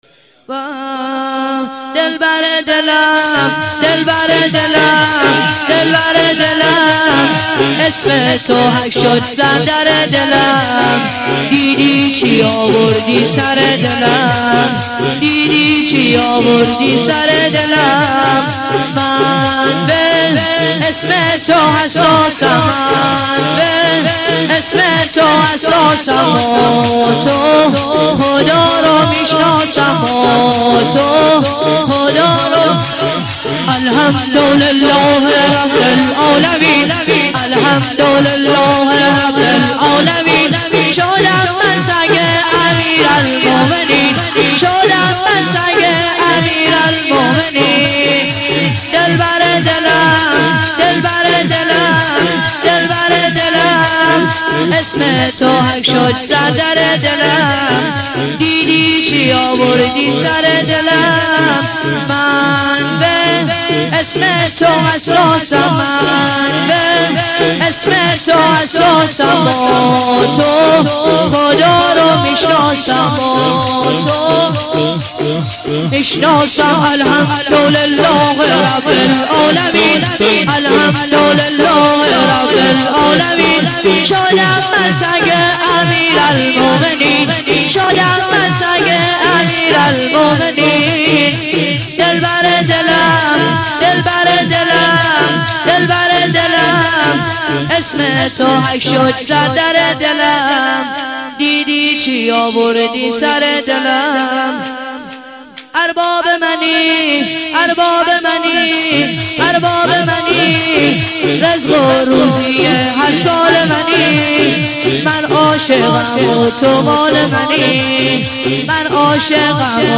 شور - دلبردلم دلبردلم